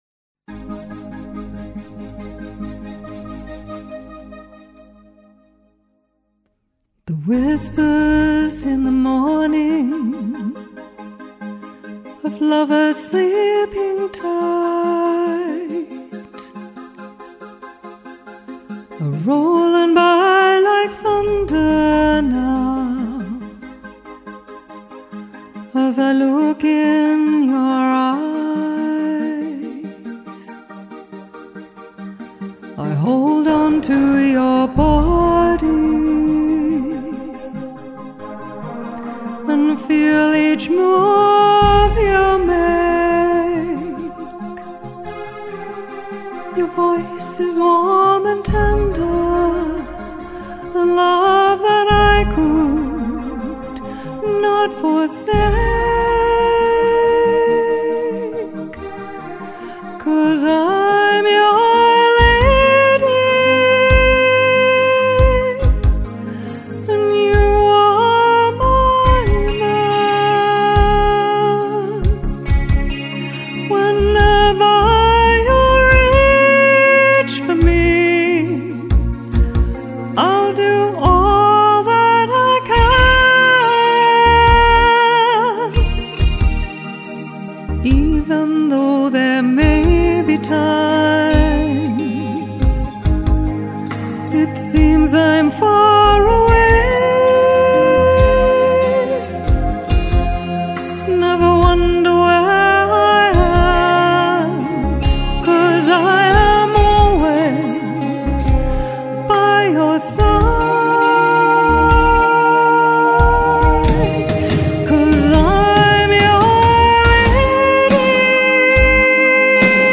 Demo tracks recorded & mixed in Vancouver, BC Canada at:
Studio Recorded November, 2017